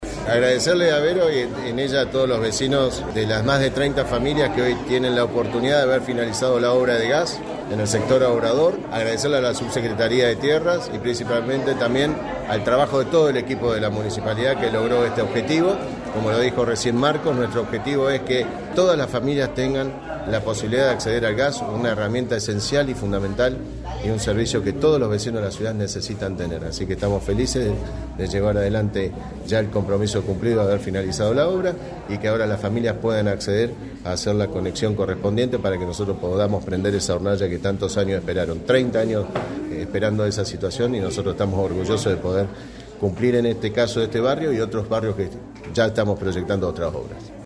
Mariano Gaido, Intendente.
Mariano-Gaido-EDITADO-Obra-de-Gas-y-Tenencias-2.mp3